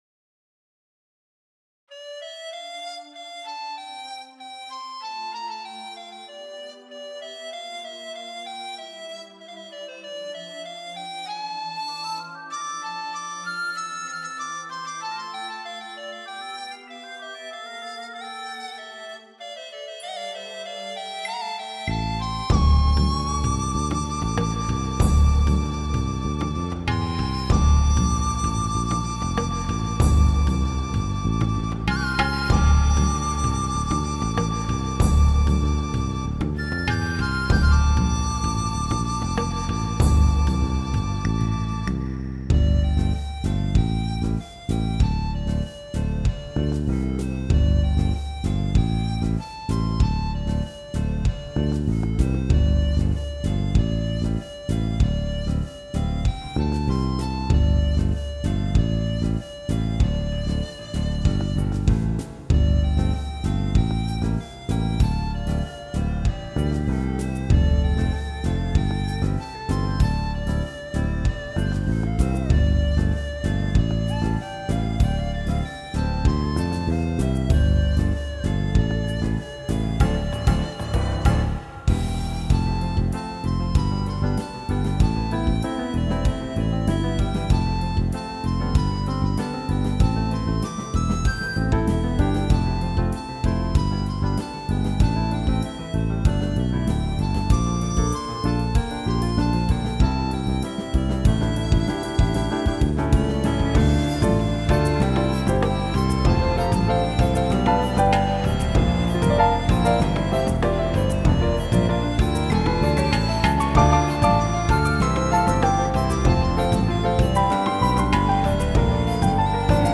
高校の頃に作り始めてずっと未完成だった民族系オリジナル曲です。ひたすら、ひたすら、好きな音を詰め込みました。
Inst -